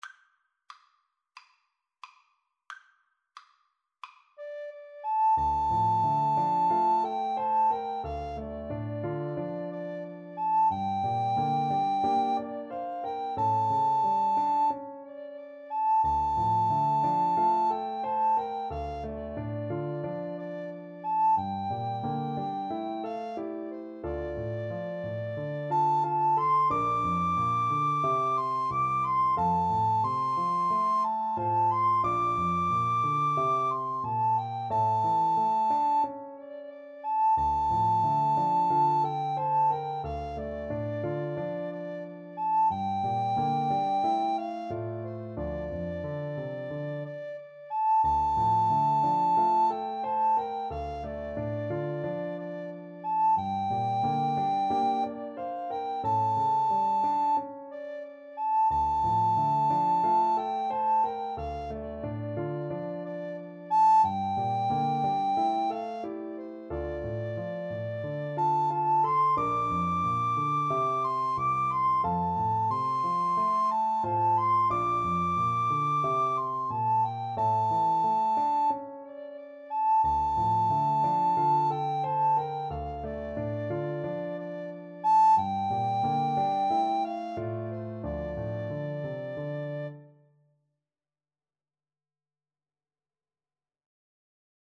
D minor (Sounding Pitch) (View more D minor Music for Recorder Duet )
Andante = c. 90